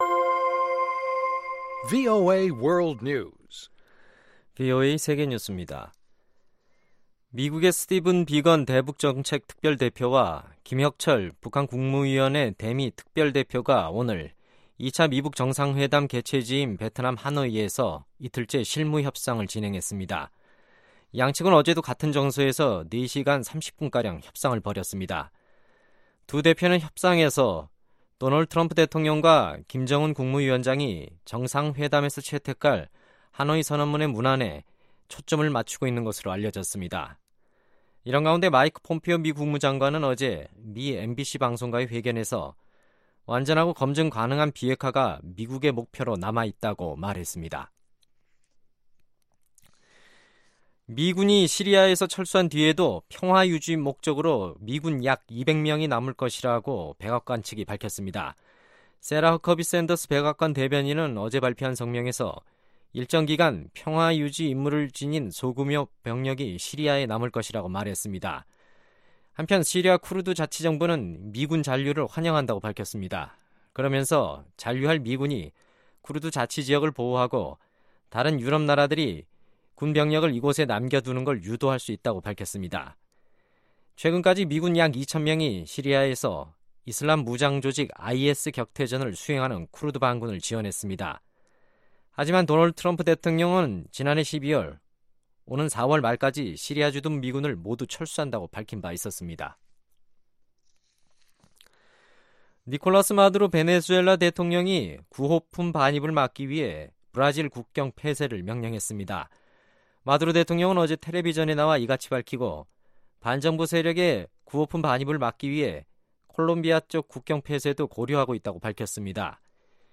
VOA 한국어 간판 뉴스 프로그램 '뉴스 투데이', 2019년 21월 22일 2부 방송입니다. 미국이 북한과 협상하는 이유는 북한이 완전한 비핵화를 선택할 가능성이 있다고 믿기 때문이라고 익명의 미 정부 고위 당국자가 밝혔습니다. 마이크 폼페오 미 국무장관은 북한의 완전하고 검증 가능한 비핵화가 미국의 목표로 남아 있다고 말했습니다.